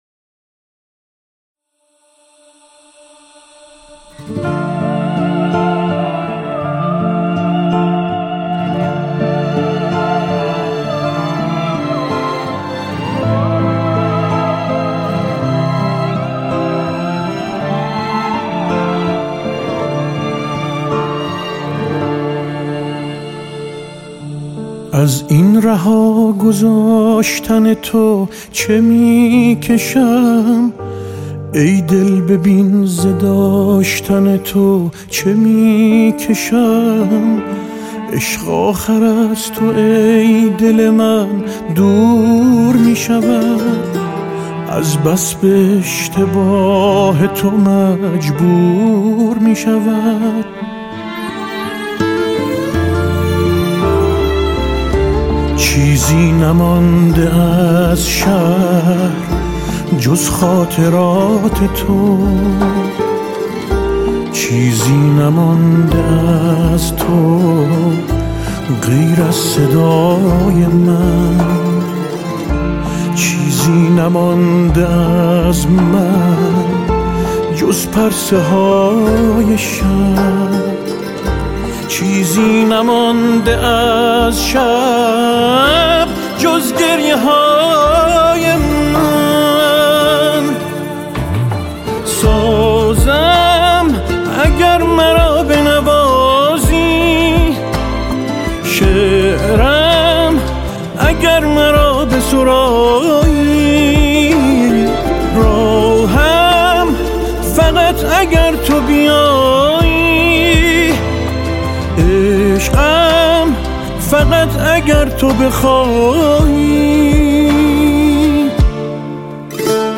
ارکستر زهی
گیتار
سه‌تار
سازهای کوبه‌ای